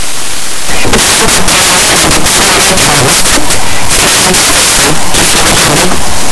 Si vous l'écoutez en secouant la tête... vous pouvez entendre le message d'origine.
Son dernier jeu a consisté à appliquer un chiffrement par transposition de messages audios.